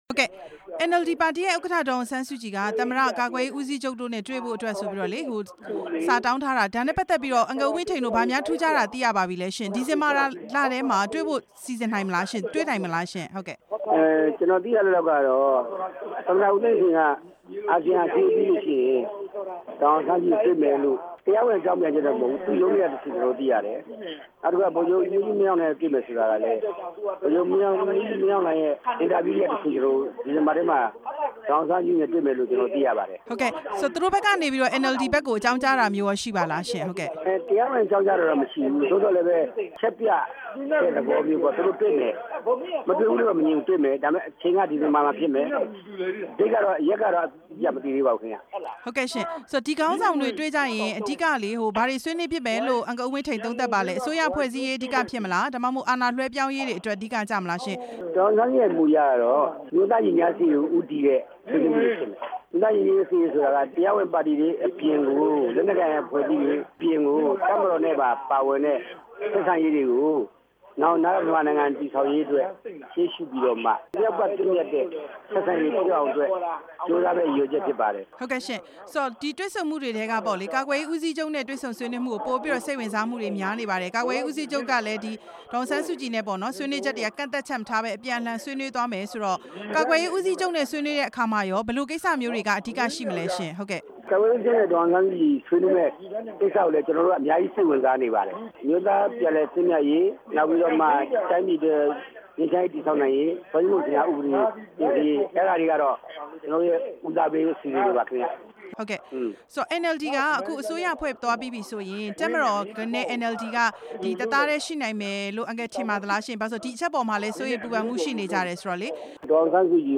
ဒေါ်အောင်ဆန်းစုကြည်နဲ့ သမ္မတ တွေ့ဆုံမယ့် အကြောင်း မေးမြန်းချက်